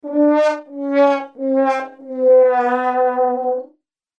Download Sad Trombone sound effect for free.
Sad Trombone